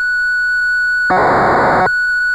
また、１曲目と９曲目に当時のビットストリームによるメッセージが隠されている。１曲目は、キーボードのタイピング音の直後。
ビットレートは当時のマイコンのデータをテープにセーブする時のスピードだと思われる。